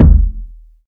Kicks
KICK.71.NEPT.wav